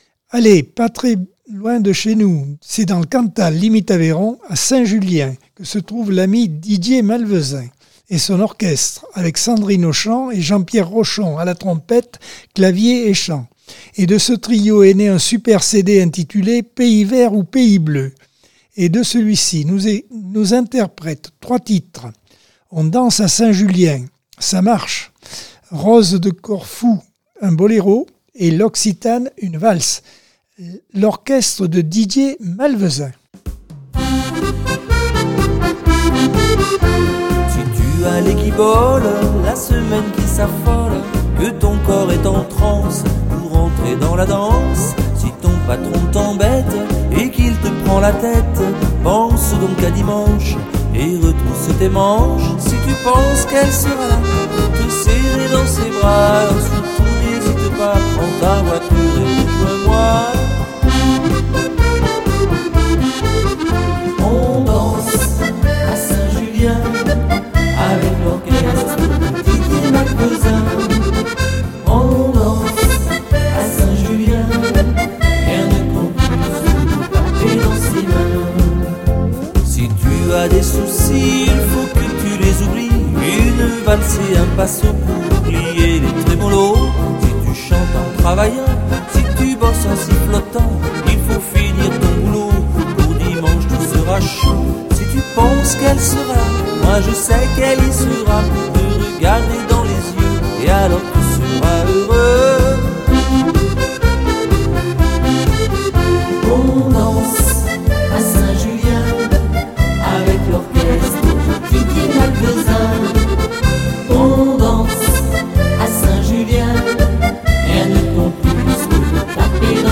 Mercredi par Accordeon 2023 sem 26 bloc 2.